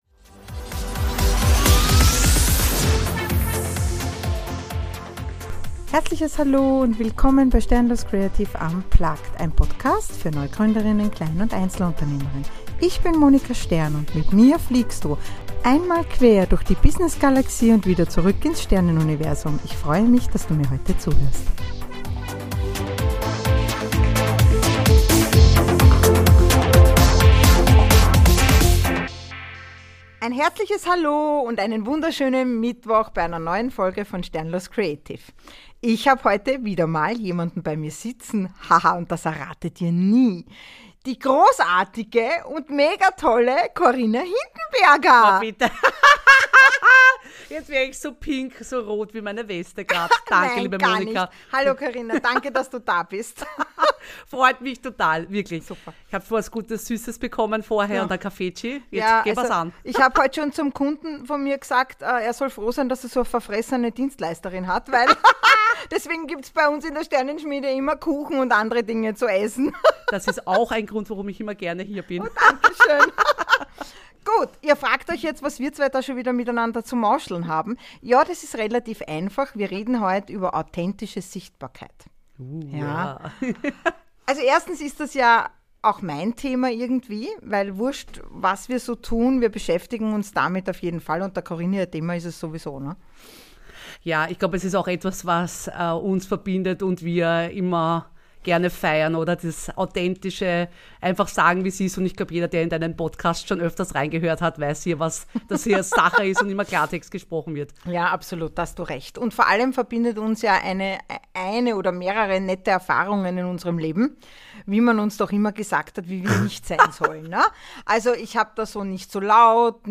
INTERVIEW Authentisch Sichtbar – Wenn zwei ehrliche, laut lachende Quasselstrippen sich treffen, kann nur eine geniale Podcastfolge draus werden.